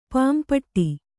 ♪ pān paṭṭi